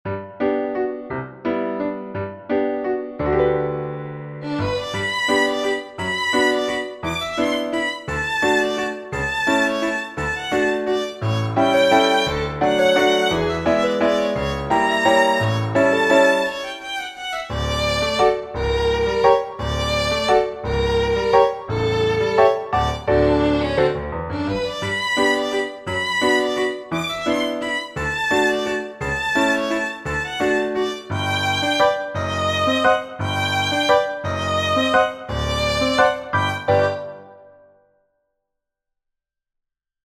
Waltz in G Major for Violin and Piano
Wrote a short waltz for violin and piano.